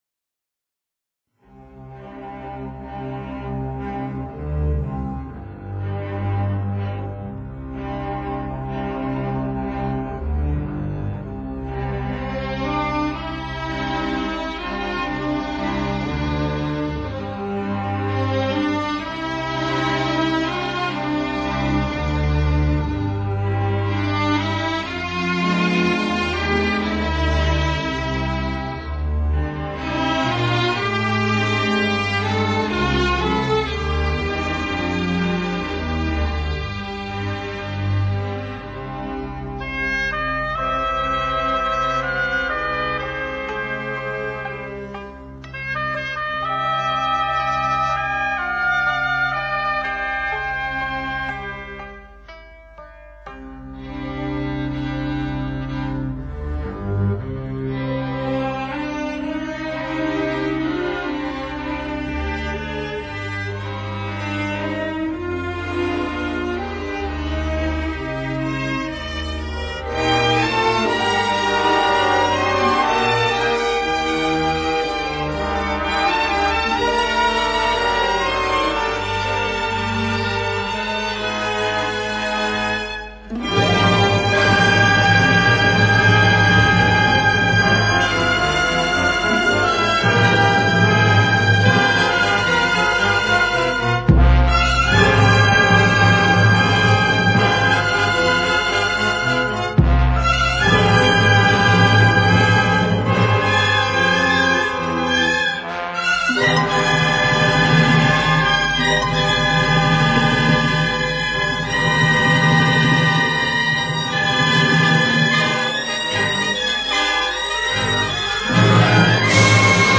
در مایه‌ی دشتی ساخته و اجرا شده است